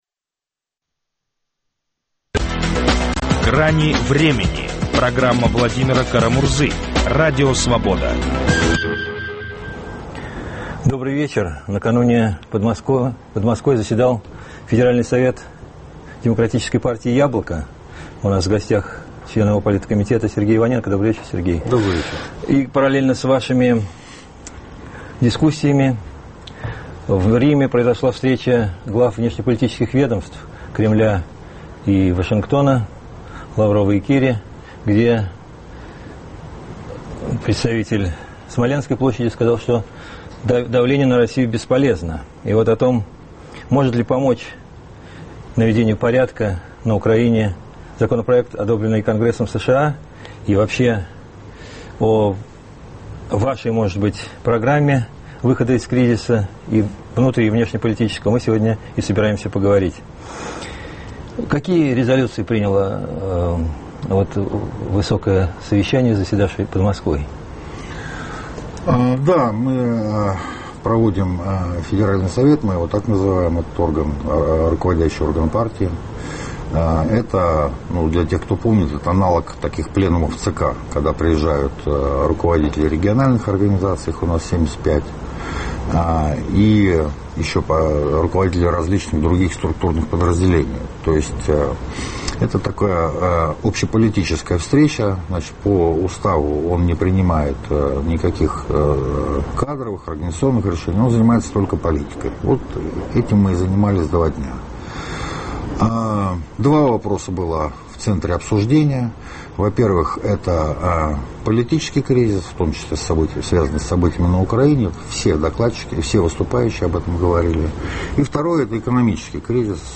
Поможет ли резолюция в поддержку Украины, принятая Конгрессом США, простым россиянам и украинцам - дискутируют политики